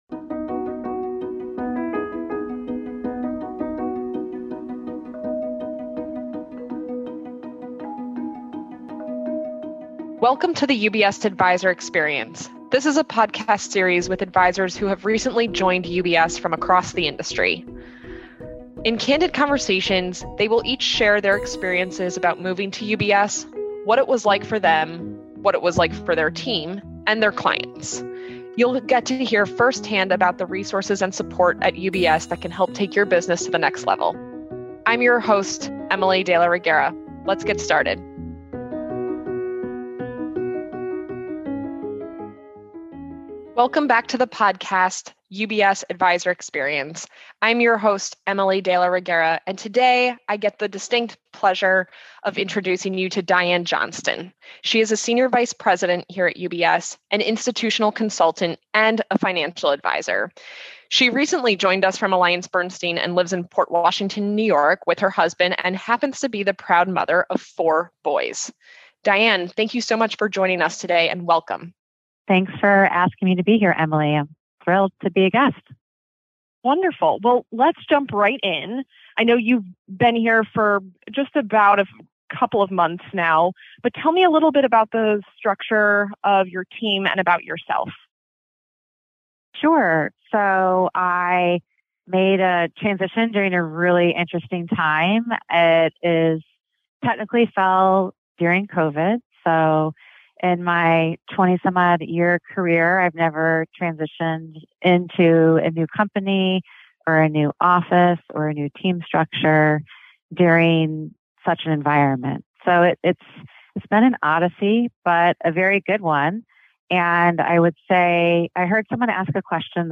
Candid conversation with advisor who joined UBS